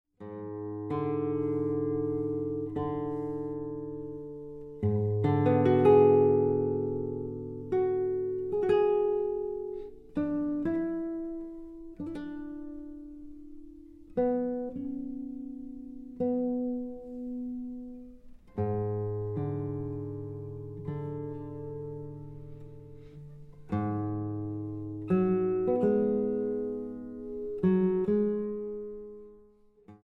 Sonata para guitarra (2011)